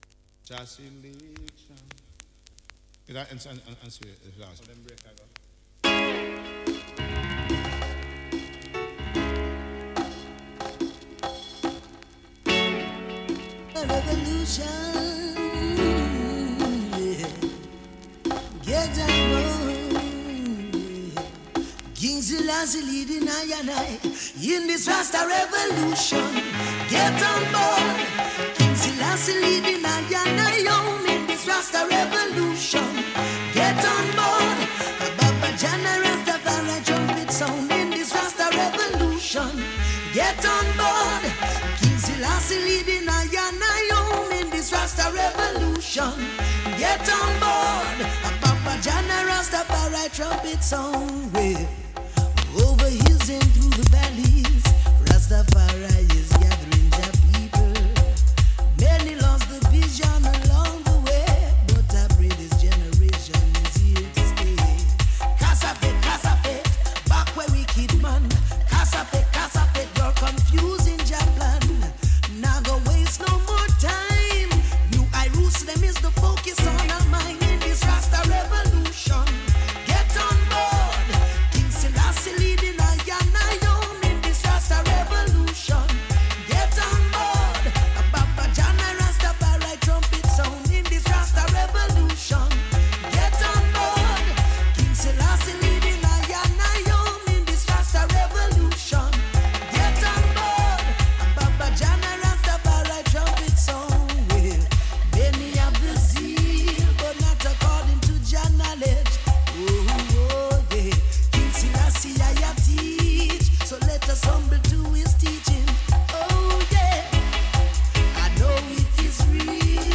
ROOTS ROCK REGGAE